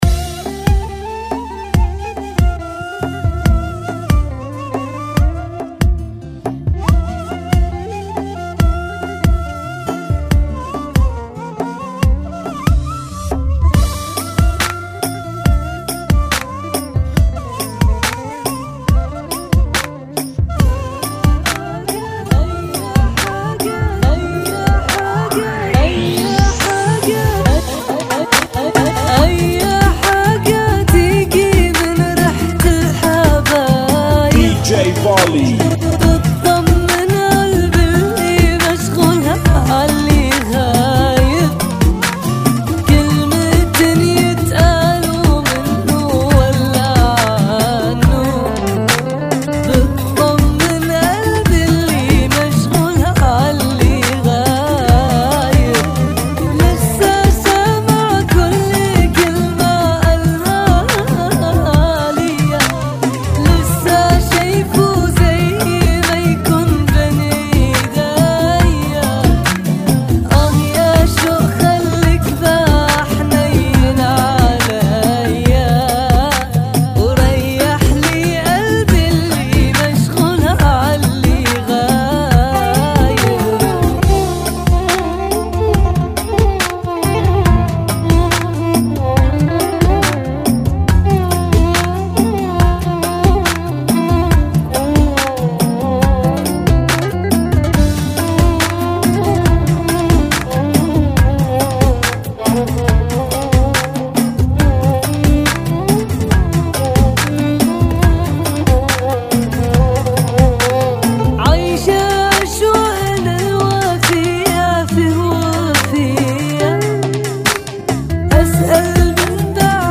70 BPM